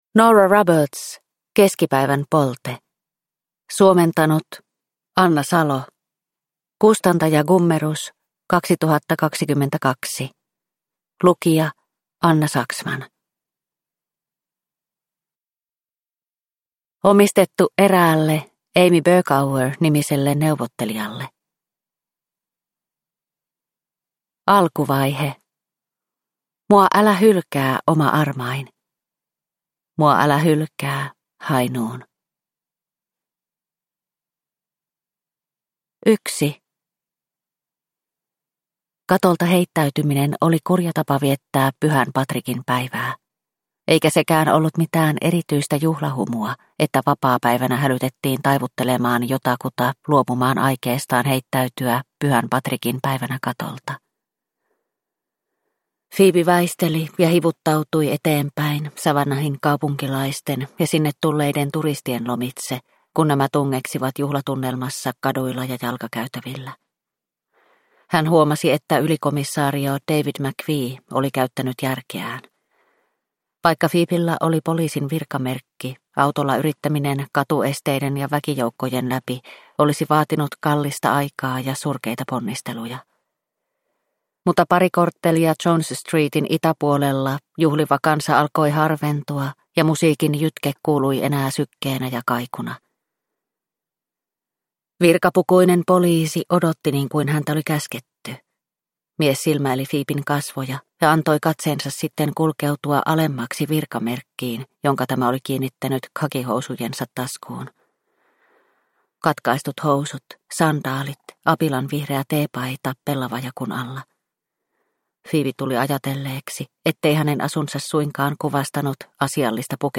Keskipäivän polte – Ljudbok – Laddas ner